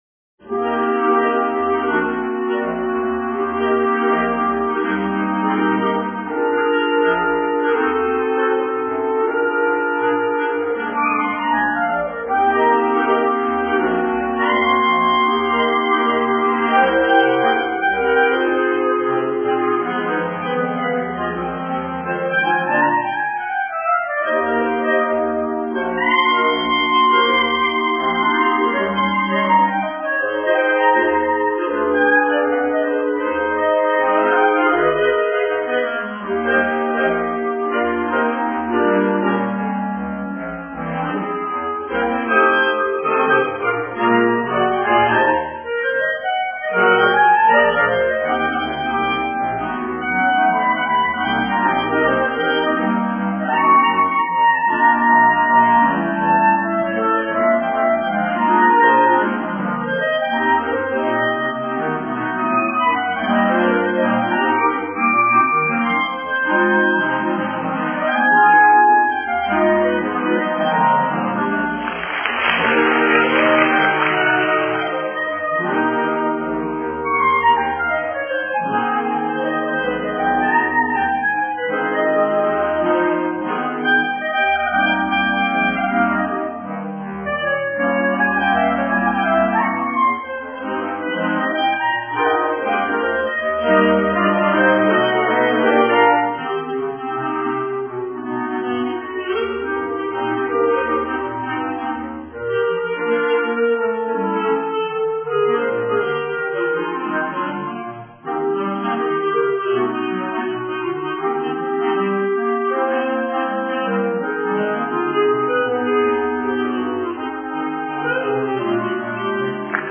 Alto cla
Bass cla